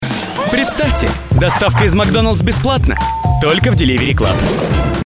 Аудиореклама для Макдоналдс